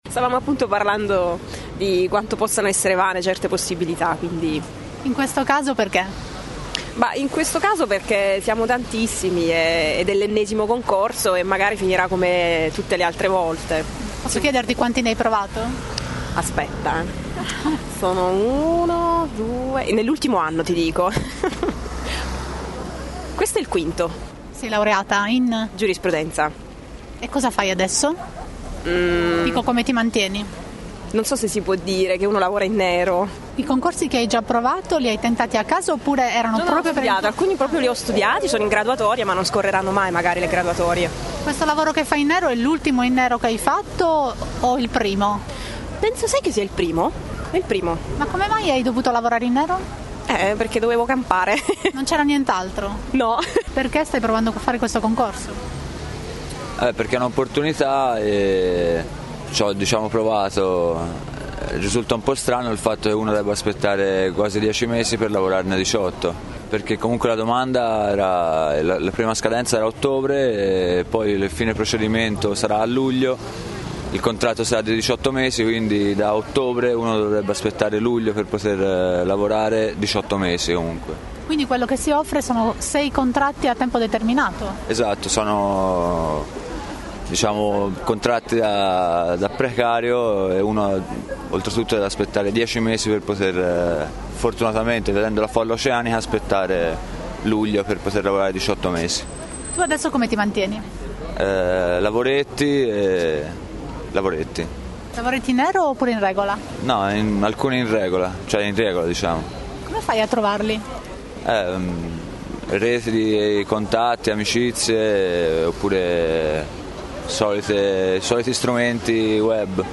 Raccontano ai nostri microfoni la loro vita da precari.
Abbiamo raccolto le loro voci nell’attesa dell’apertura dei cancelli.